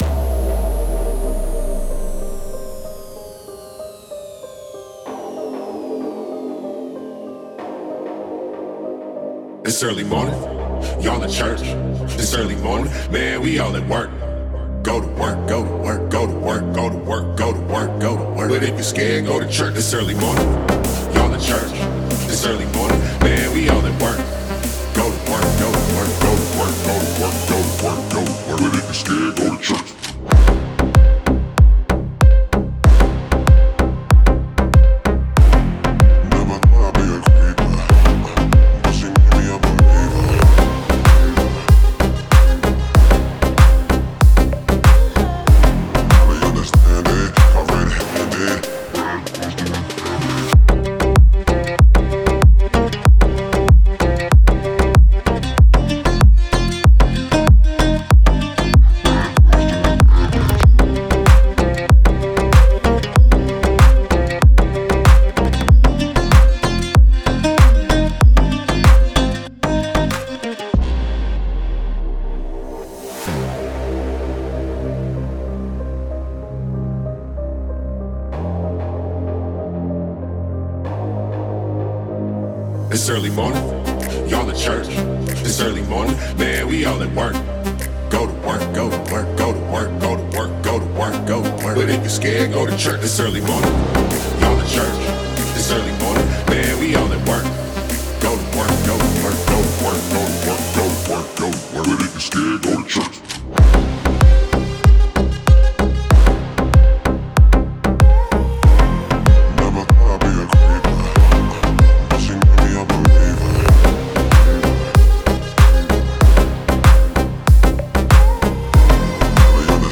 это динамичная трек в жанре electronic dance music